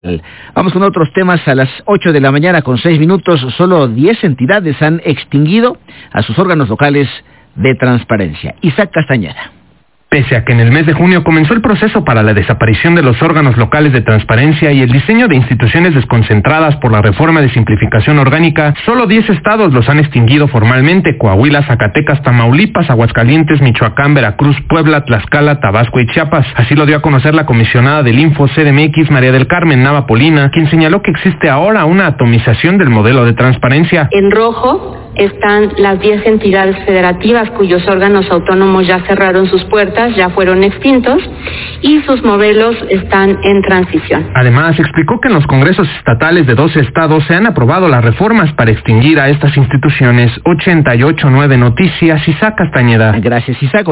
reportero: